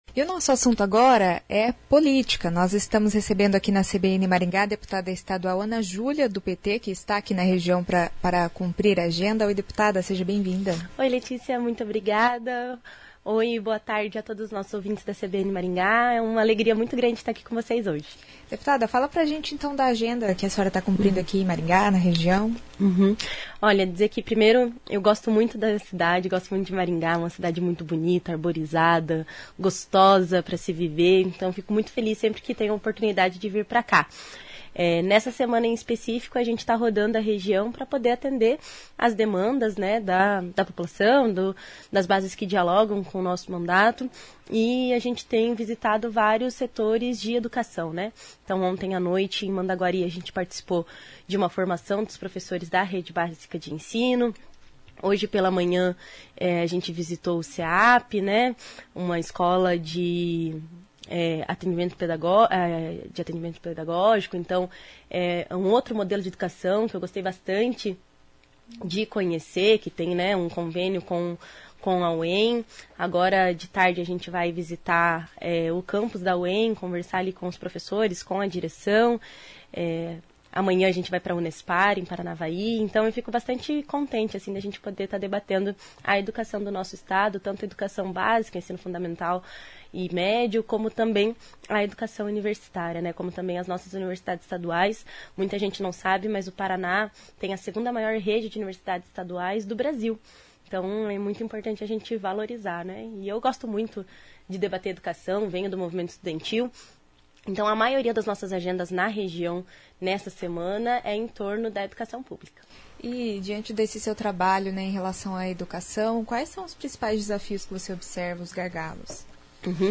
A Deputada estadual, Ana Júlia Ribeiro (PT), falou das pautas e projetos em debate na Assembleia.